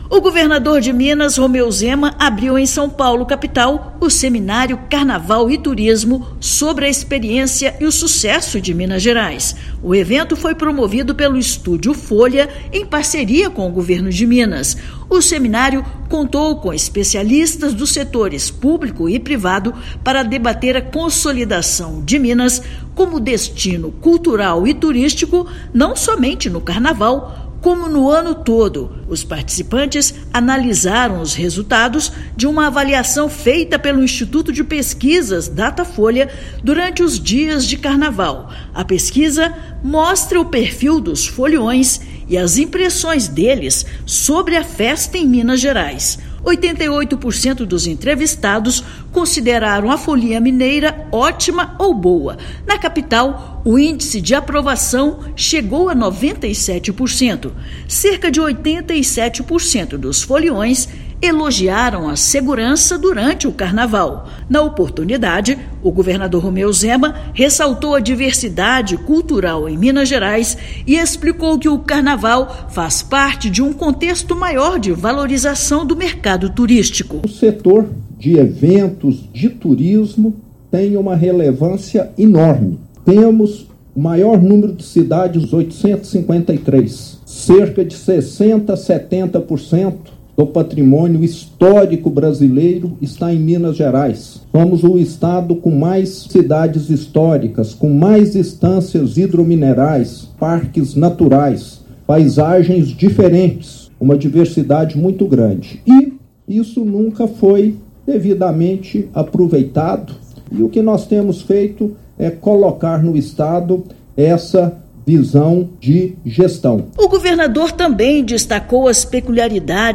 [RÁDIO] Pesquisa mostra que 88% dos turistas aprovam o Carnaval da Liberdade de Minas Gerais
Evento realizado em São Paulo trouxe resultados do levantamento realizado pelo Datafolha com os foliões. Ouça matéria de rádio.